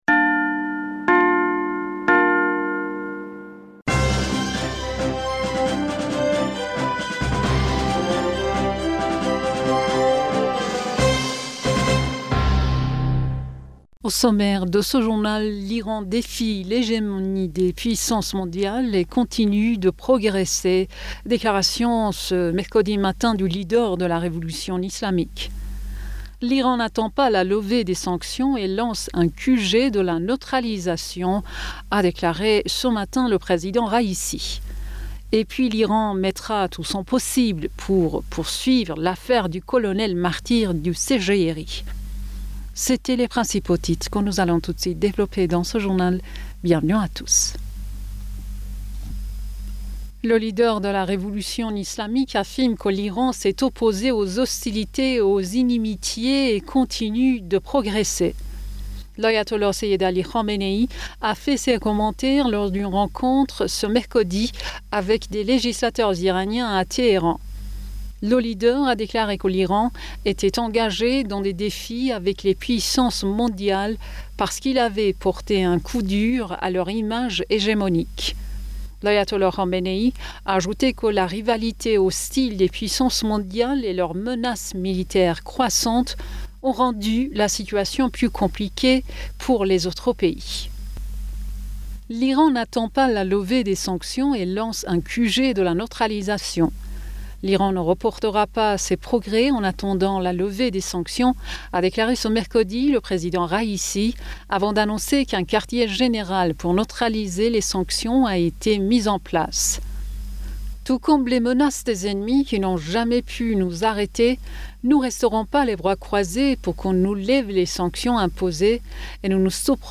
Bulletin d'information Du 25 Mai 2022